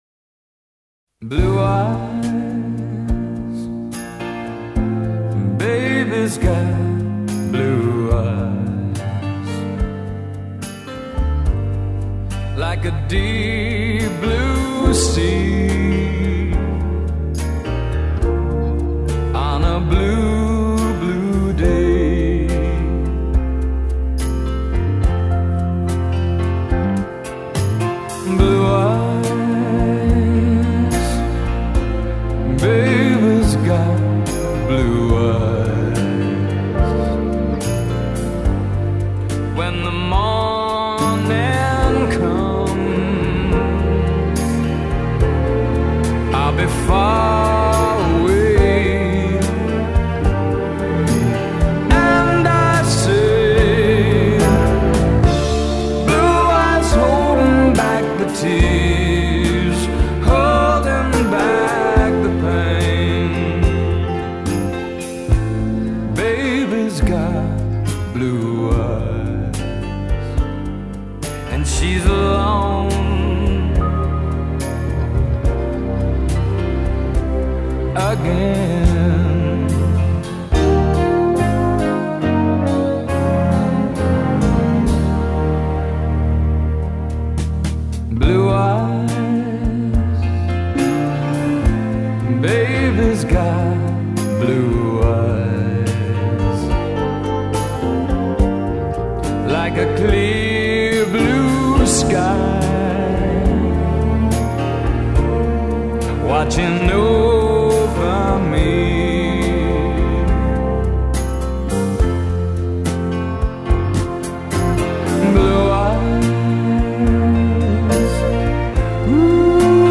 搖滾爵士